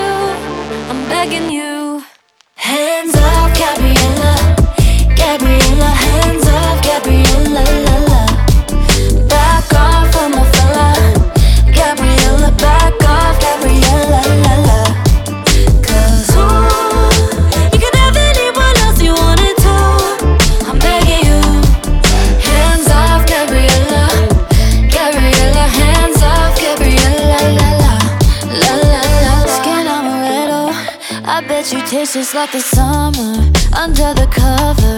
Скачать припев
2025-06-27 Жанр: Поп музыка Длительность